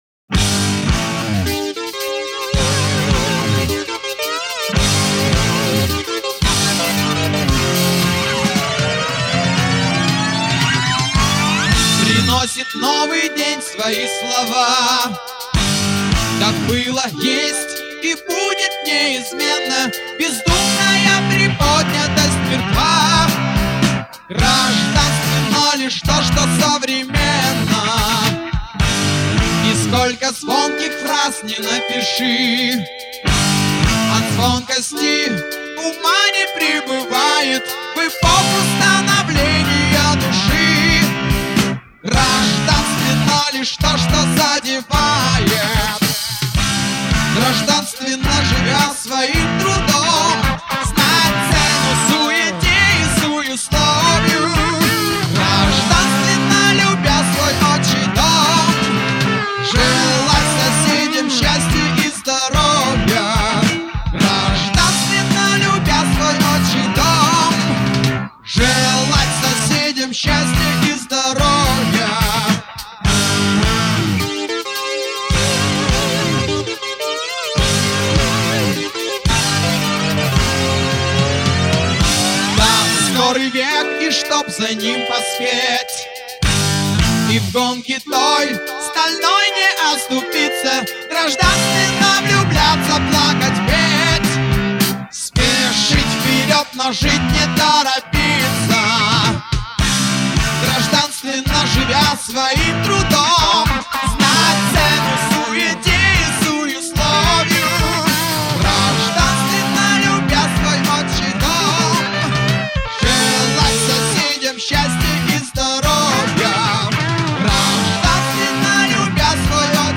Жанр: Rock
Стиль: Hard Rock, Soft Rock, Pop Rock